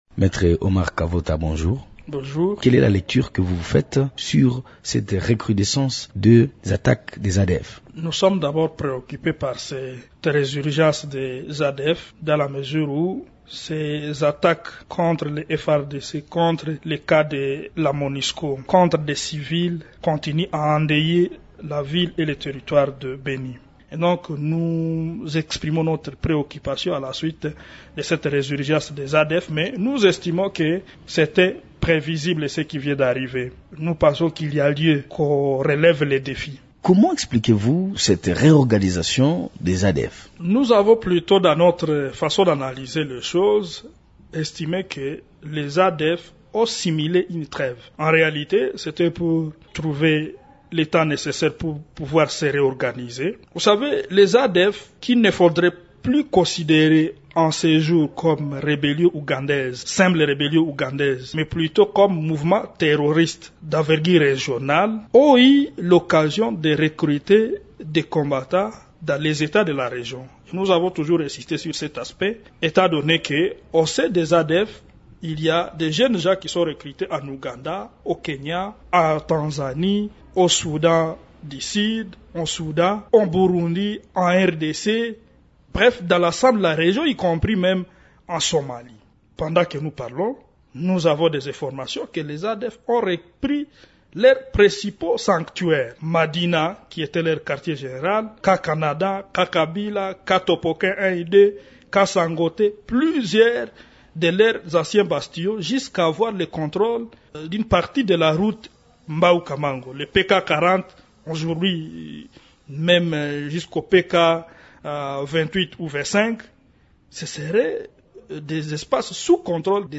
Il répond aux questions